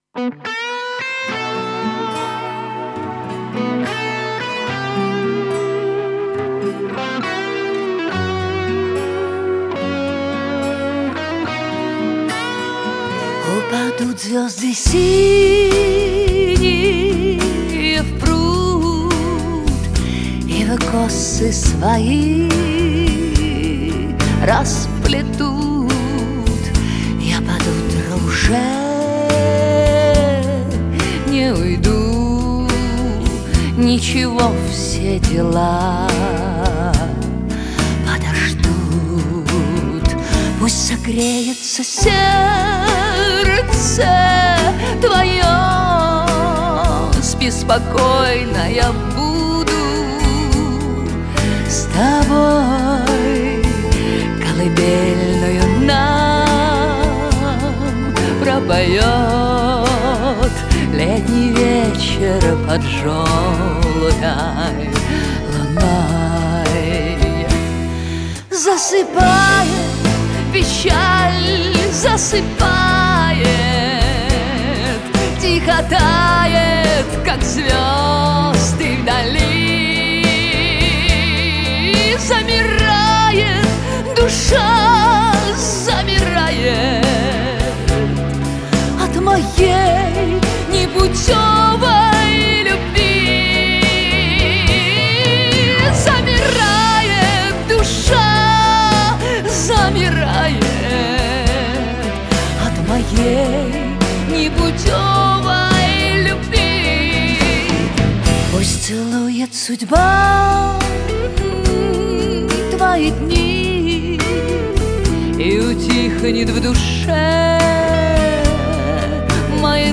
Русский романс…